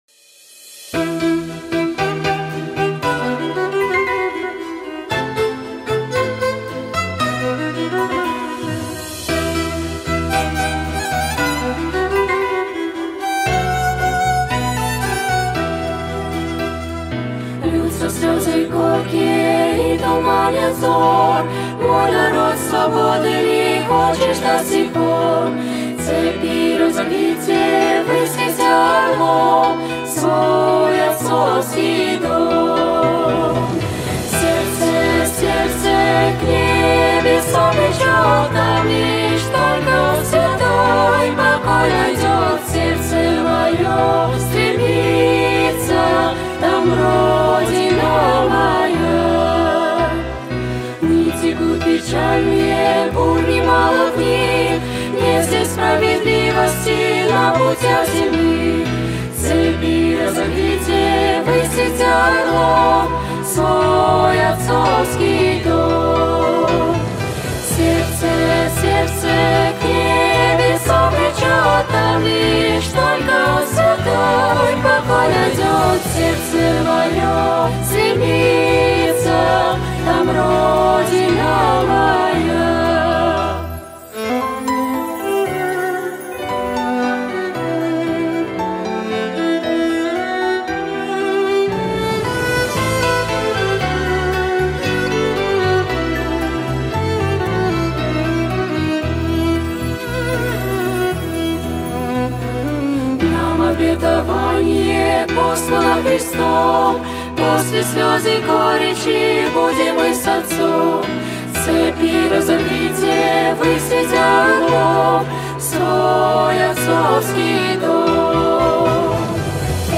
1196 просмотров 524 прослушивания 119 скачиваний BPM: 115